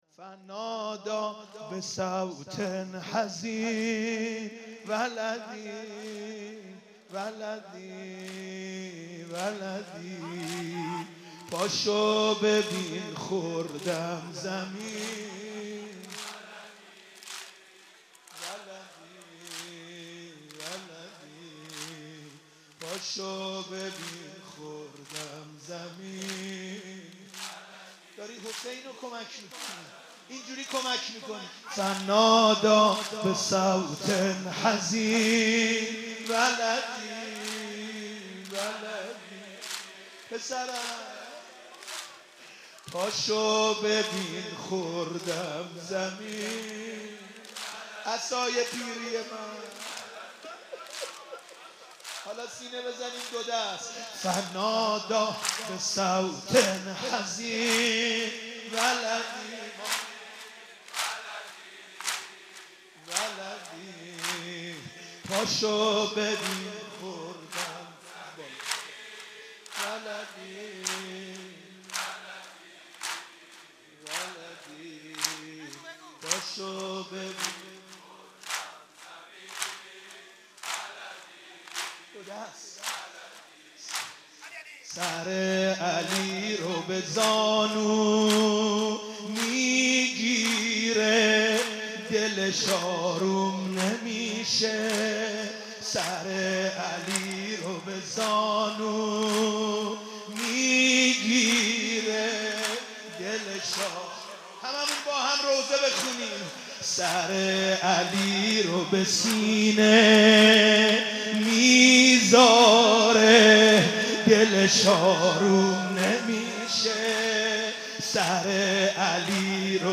مداحی